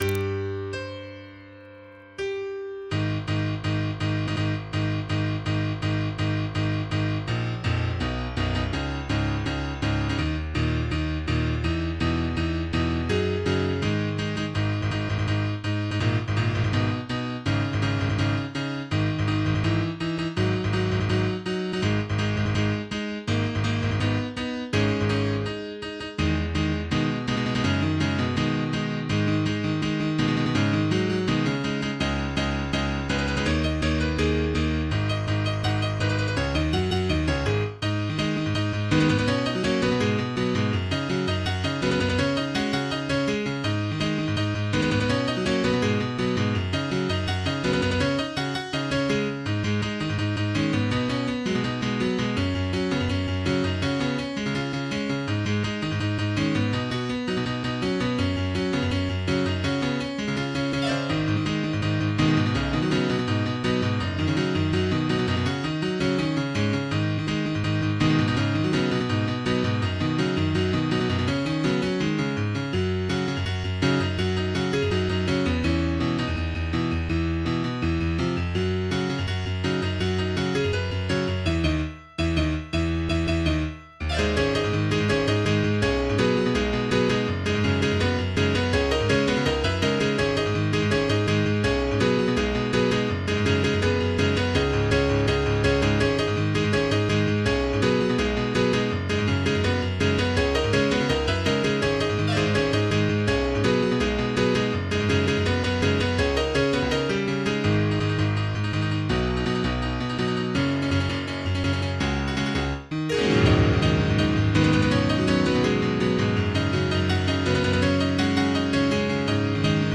MIDI 33.27 KB MP3